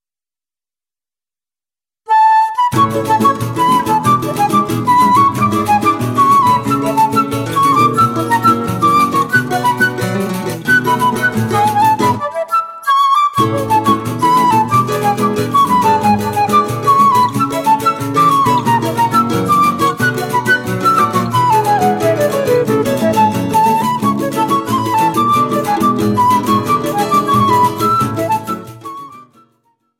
flute
Choro ensemble